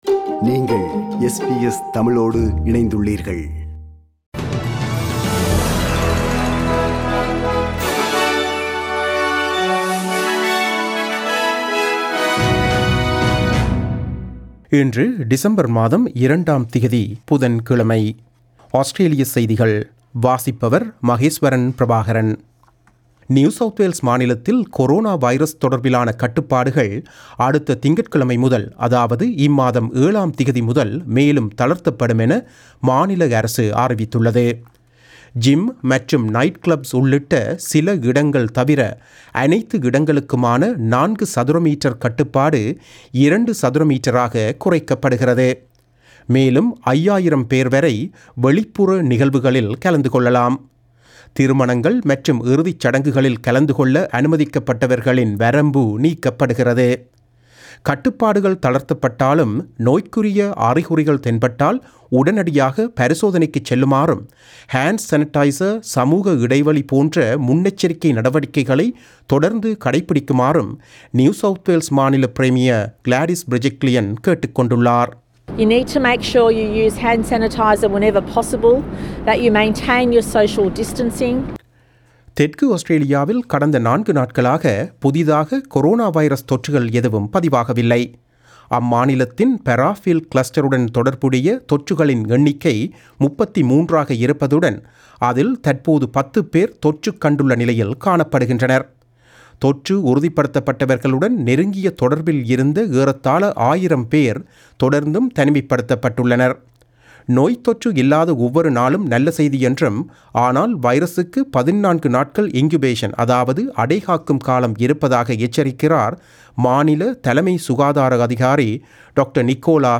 Australian news bulletin for Wednesday 02 December 2020.